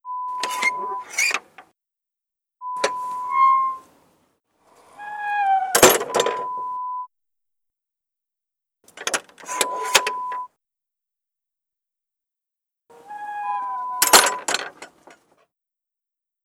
Sonido de la apertura de una puerta de hierro. Acompaña a dicho sonido un pitido espaciado y constante no relacionado con dicha actividad
chirrido
Sonidos: Acciones humanas